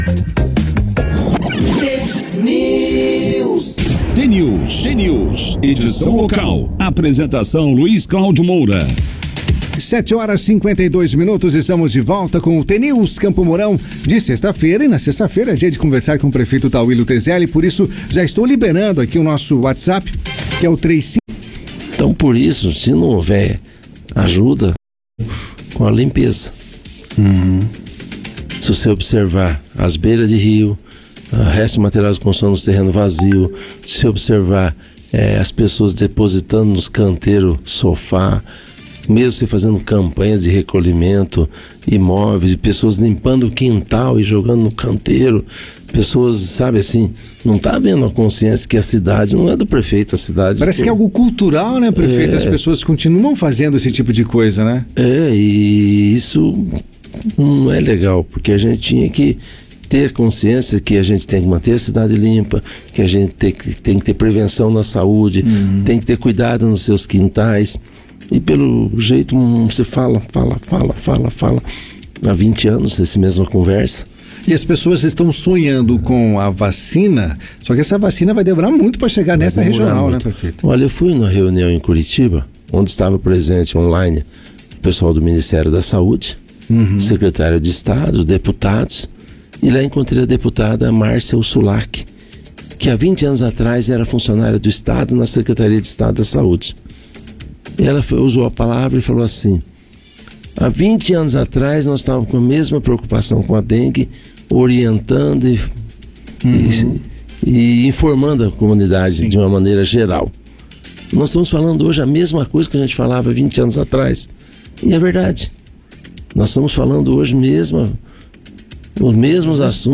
Prefeito Tauillo na Rádio T FM.
Nesta sexta-feira, dia 23, como acontece há mais de duas décadas, Tauillo Tezelli, atual prefeito de Campo Mourão, participou do jornal T News, da Rádio T FM.
Dengue, Santa Casa e obras no municípios, alguns dos temas tratados no programa. Clique no player abaixo para ouvir (corte no sinal da internet interrompeu segundos iniciais da entrevista).